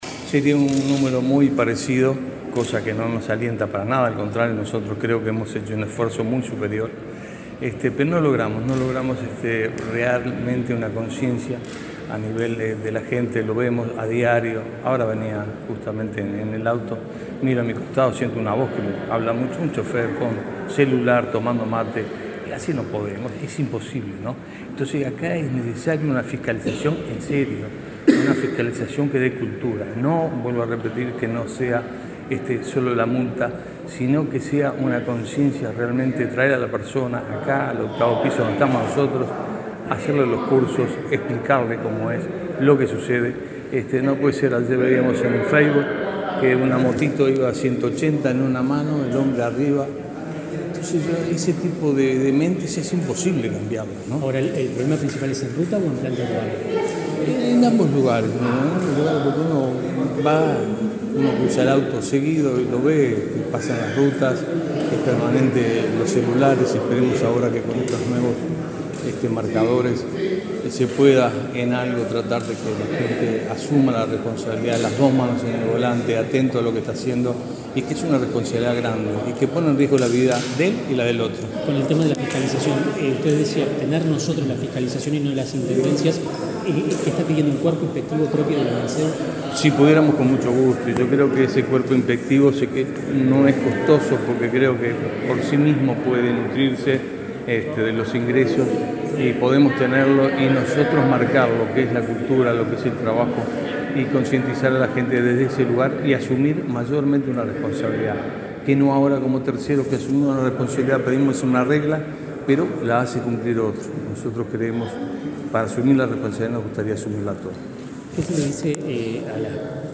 Declaraciones a la prensa de director de Unasev, Carlos Manzor
Declaraciones a la prensa de director de Unasev, Carlos Manzor 21/11/2022 Compartir Facebook X Copiar enlace WhatsApp LinkedIn El director de la Unidad Nacional de Seguridad Vial (Unasev), Carlos Manzor, participó, en la Torre Ejecutiva, en el acto por el Día Nacional de las Víctimas de Siniestros de Tránsito 2022. Antes dialogó con la prensa.